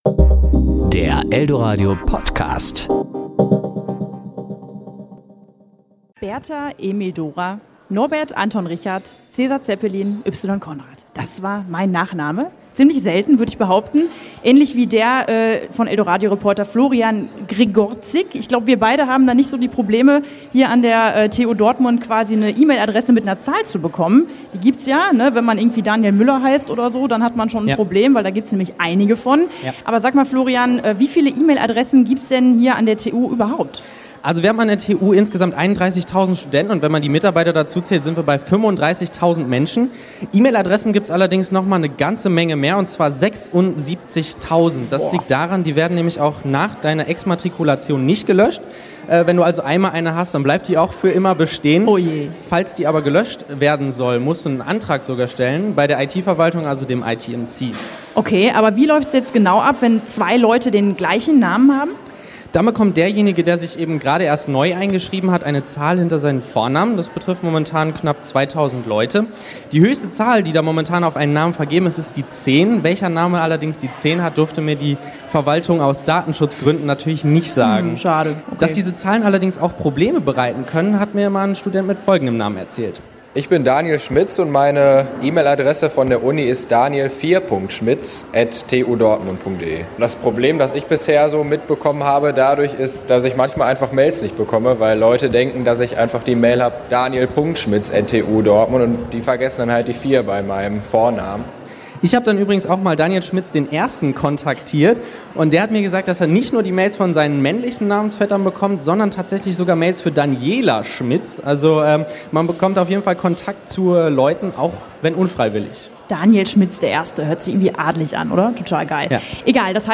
Kollegengespräch  Sendung